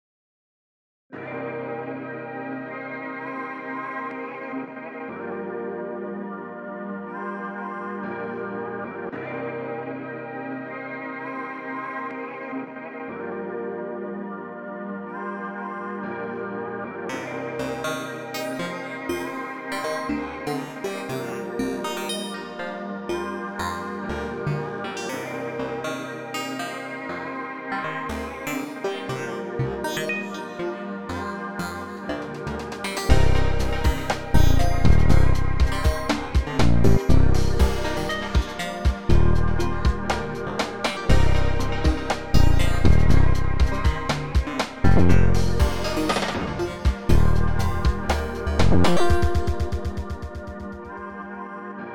ZDF LP and EQ make for nice and easy bass tones.